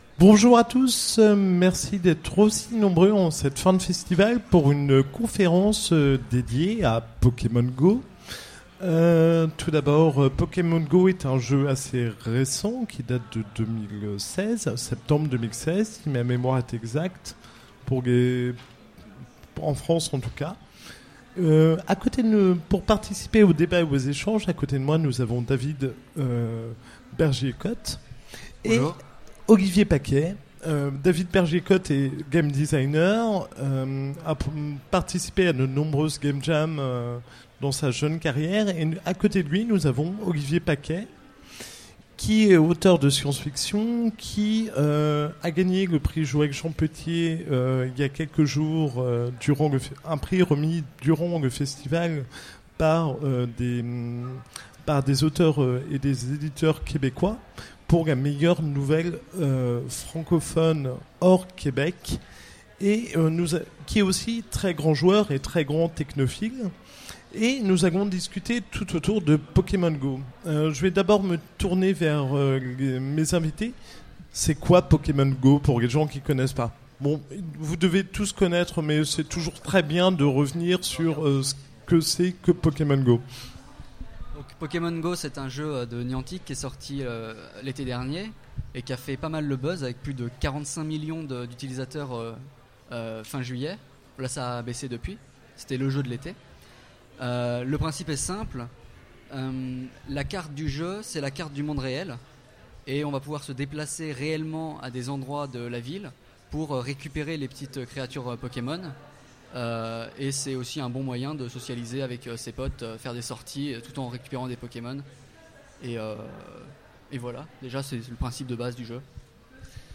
Utopiales 2016 : Conférence Pokemon Go